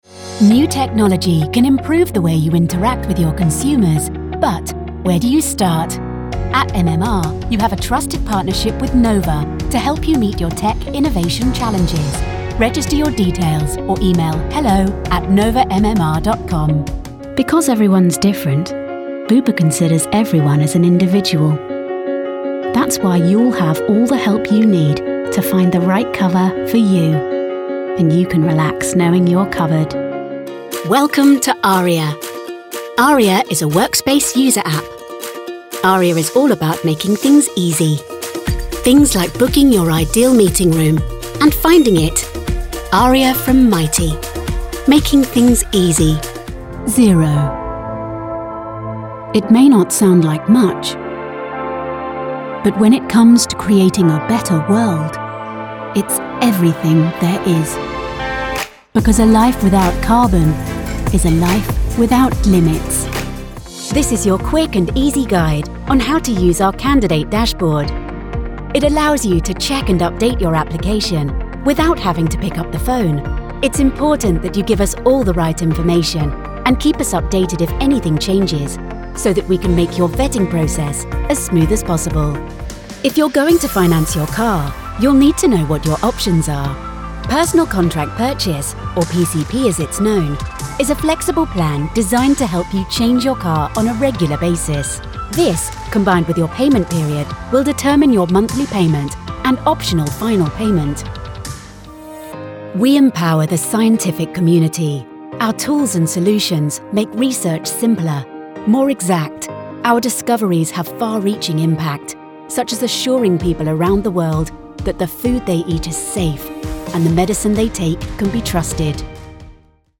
Engels (Brits)
Natuurlijk, Veelzijdig, Vriendelijk, Warm, Zakelijk
Corporate